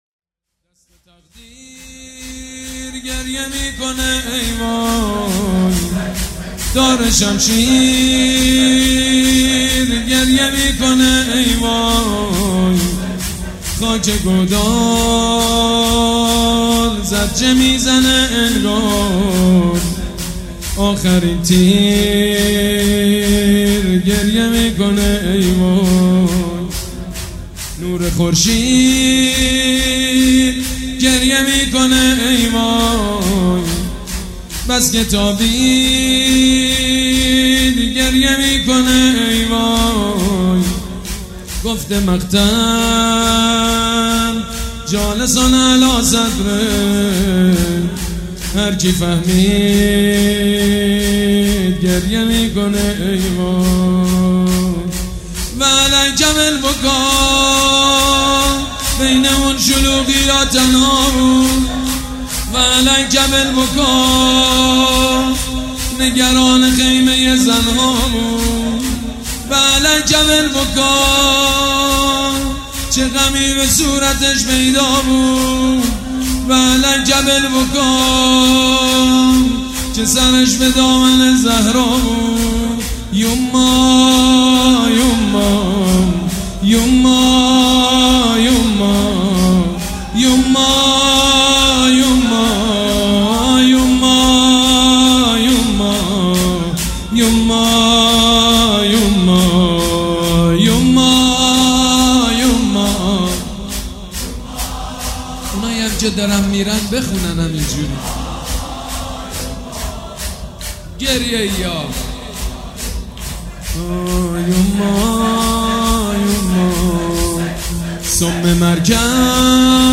مداحی شور و زیبا با نوای سید مجید بنی فاطمه ویژه محرم وصفر
مداحی سوزناک مراسم اربعین98